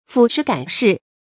撫時感事 注音： ㄈㄨˇ ㄕㄧˊ ㄍㄢˇ ㄕㄧˋ 讀音讀法： 意思解釋： 謂感念時事，傷懷往事。